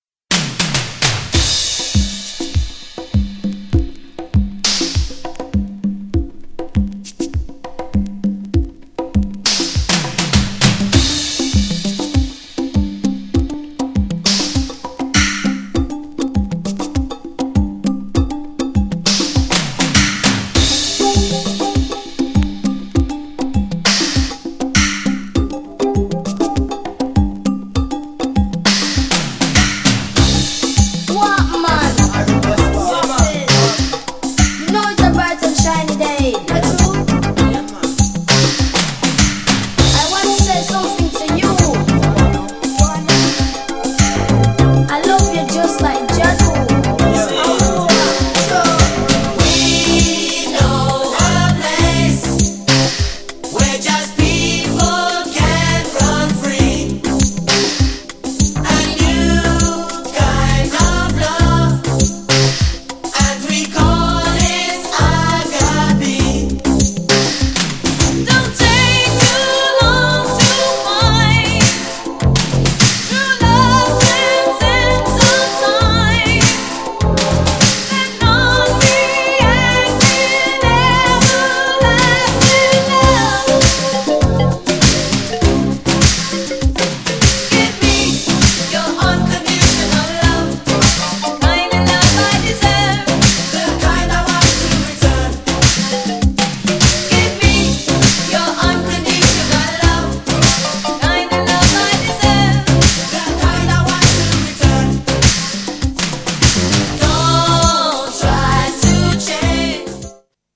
ハートフルなカリビアン・レゲエアレンジ!! GARAGE CLASSIC!!
GENRE Dance Classic
BPM 136〜140BPM